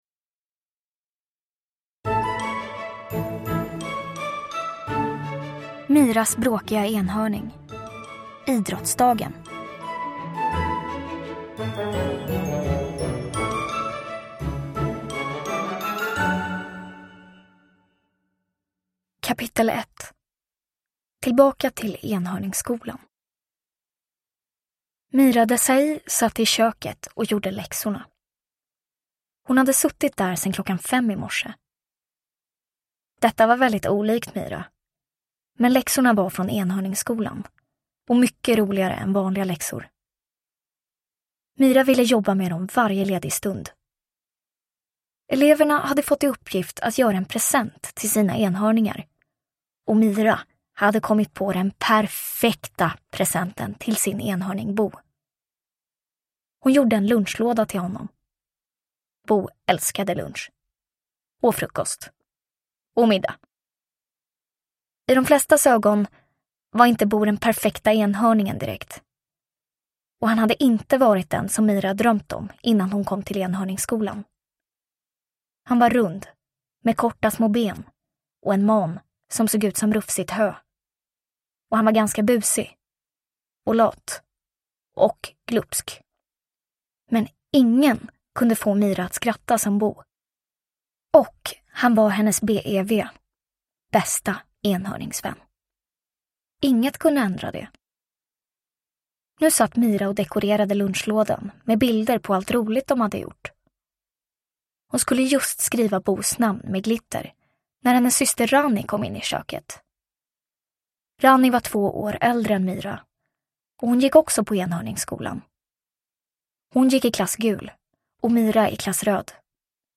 Idrottsdagen – Ljudbok